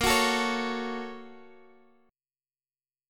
A#mM7#5 Chord